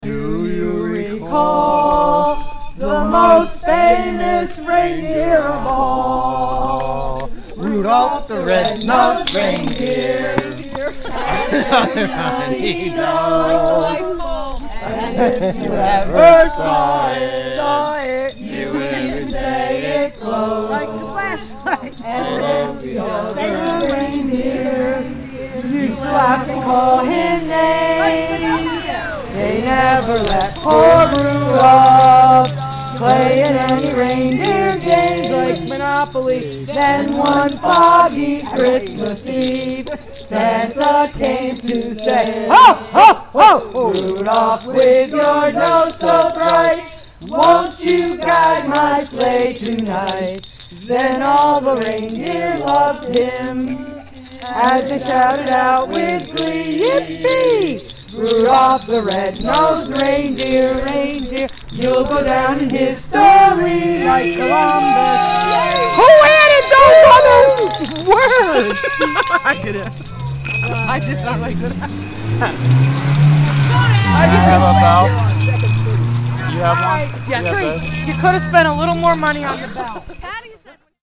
Athens carolers: Rudolph the Red-Nosed Reindeer (Audio)